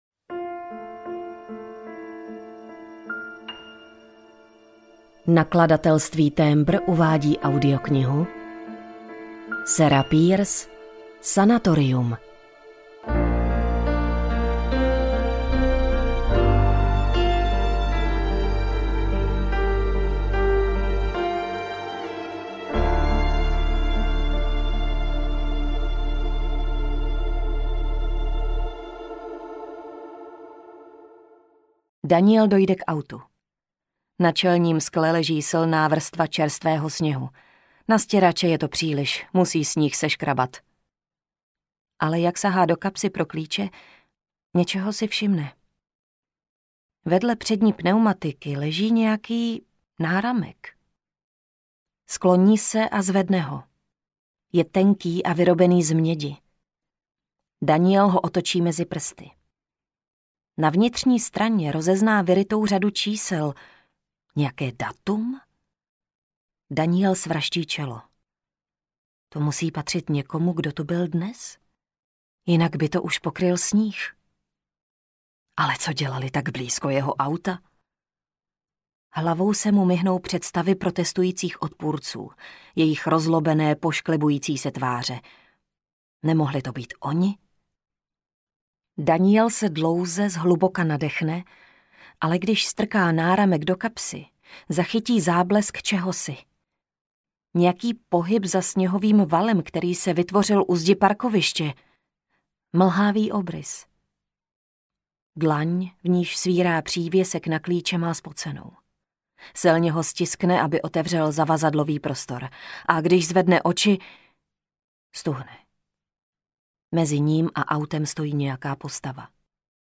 Sanatorium audiokniha
Ukázka z knihy